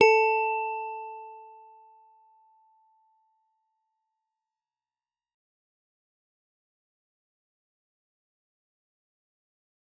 G_Musicbox-A4-f.wav